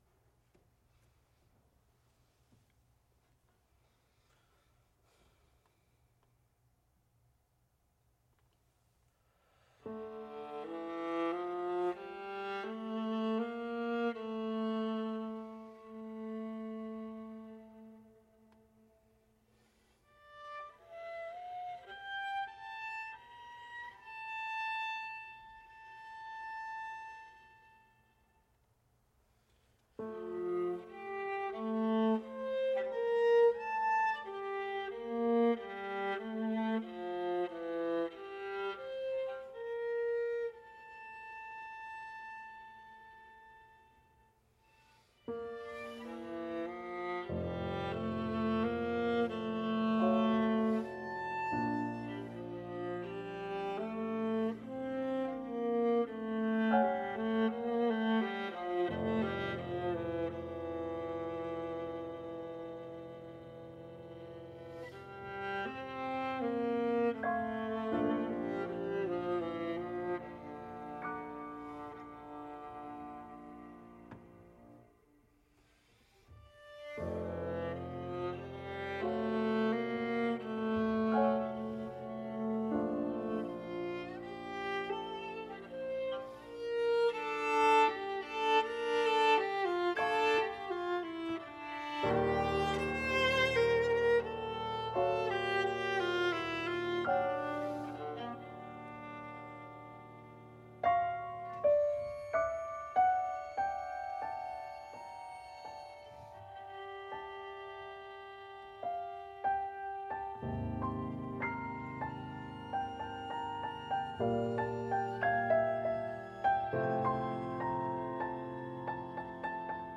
Roulette, NYC